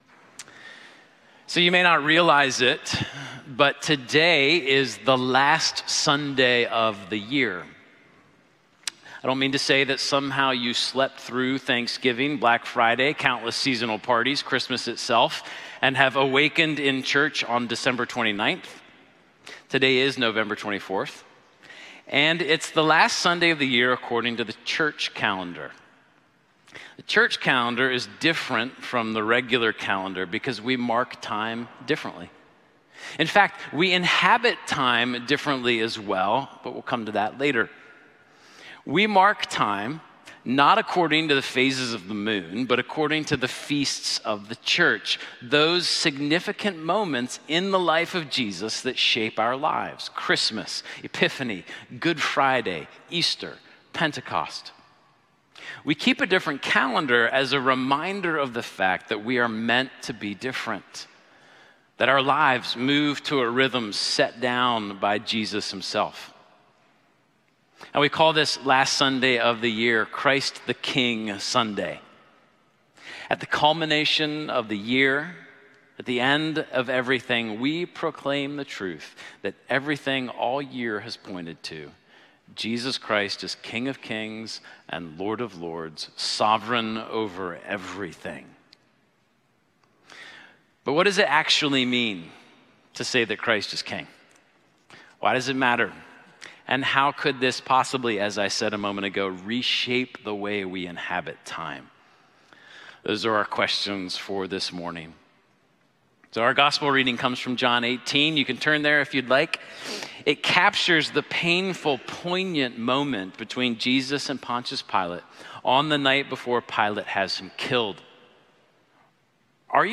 Please enjoy the regular Sunday sermons presented each week from Holy Trinity Anglican.